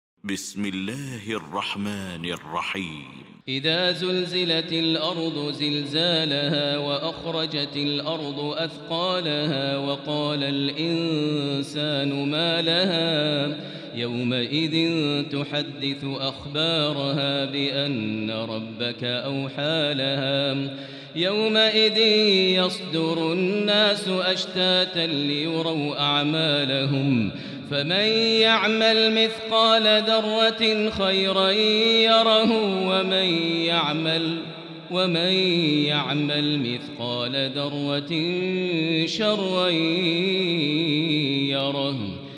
المكان: المسجد الحرام الشيخ: فضيلة الشيخ ماهر المعيقلي فضيلة الشيخ ماهر المعيقلي الزلزلة